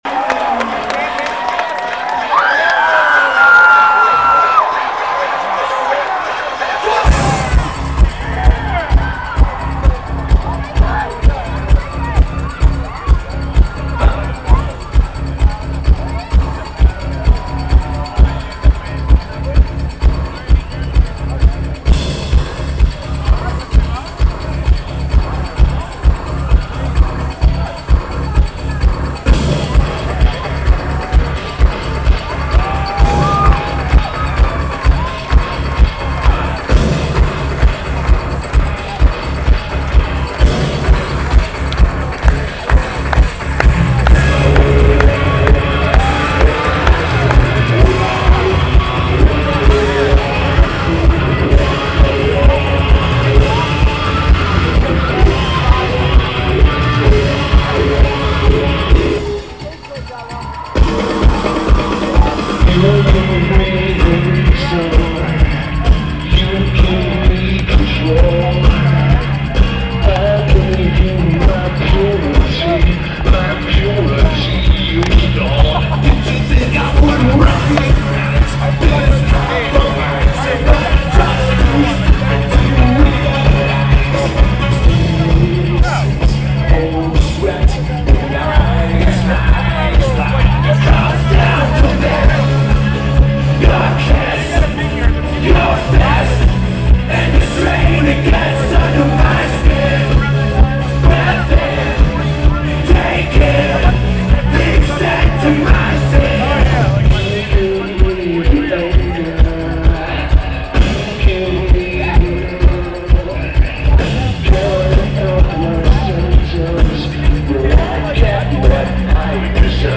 Merriweather Post Pavillion
Drums
Bass
Guitar
Vocals/Guitar/Keyboards
But the bass overloads the recording at certain points.